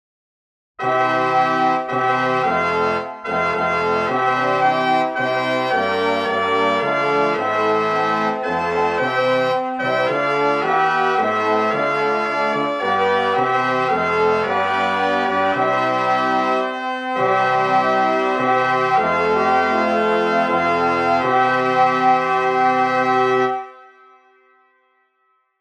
音源は、比較のために、全て金管にしています。